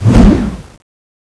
stab.wav